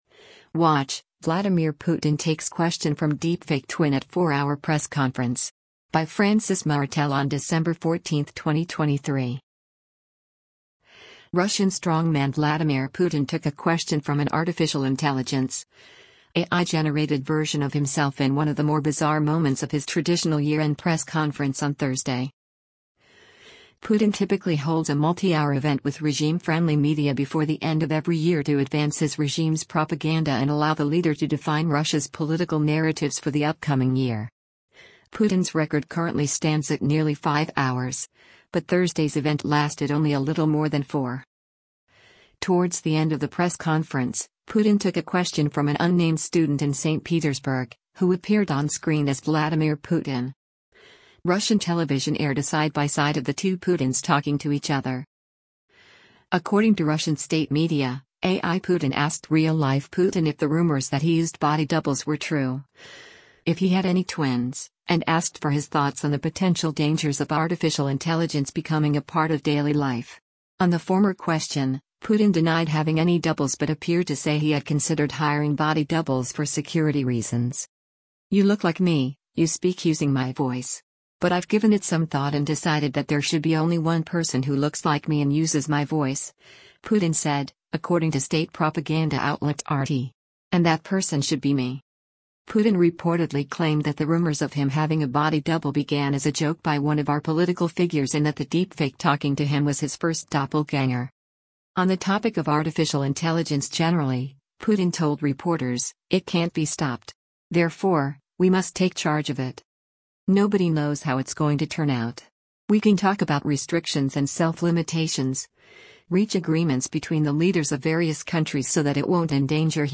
Russian strongman Vladimir Putin took a question from an artificial intelligence (AI)-generated version of himself in one of the more bizarre moments of his traditional year-end press conference on Thursday.
Russian television aired a side-by-side of the two Putins talking to each other.